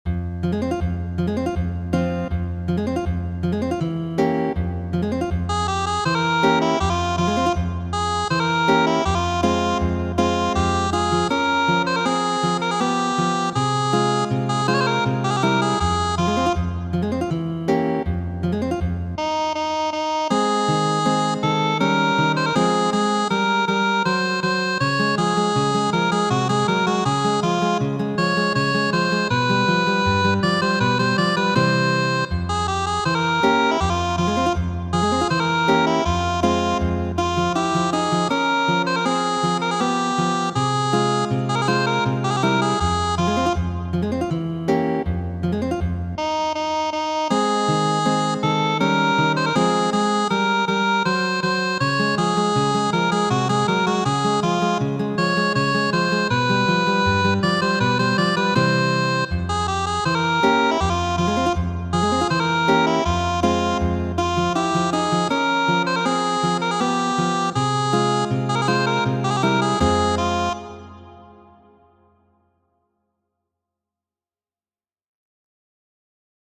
Muziko:
Knabino, kio pri honto?, verkita por kanto kaj gitaro de Fernando Sor.